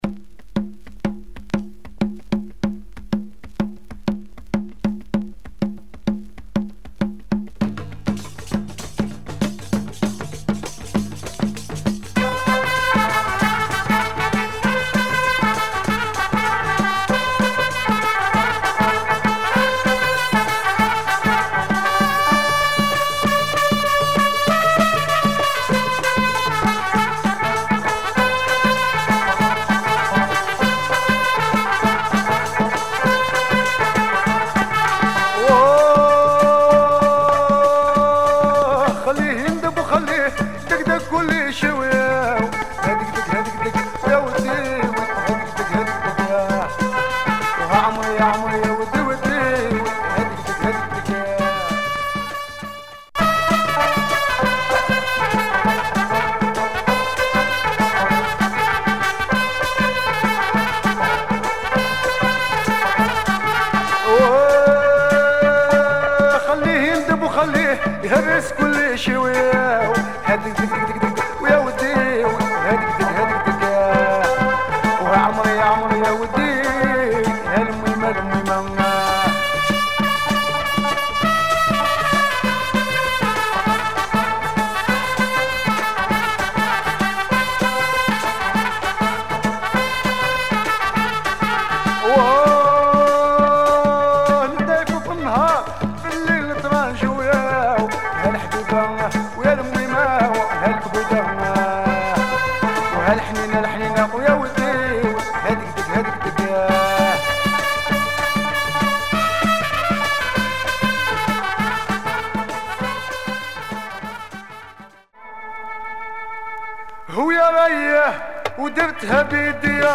Algerian proto rai
trumpeter